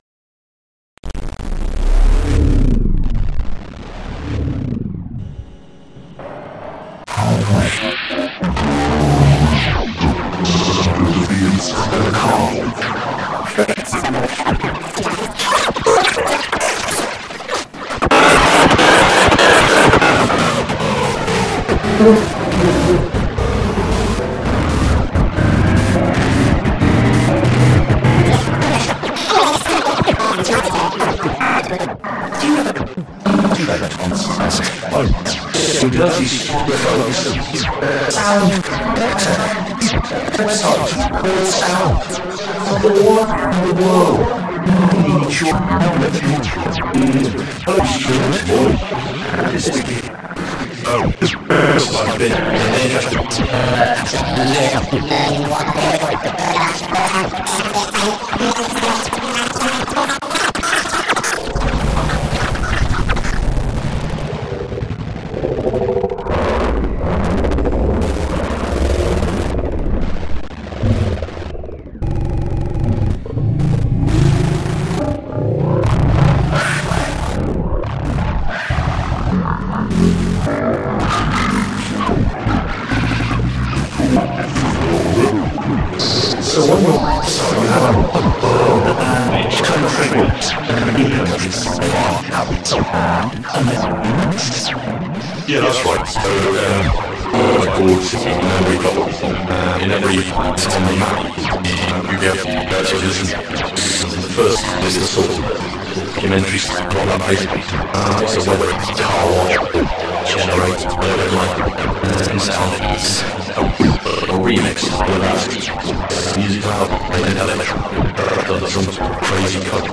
BBC Oxford interview - remixed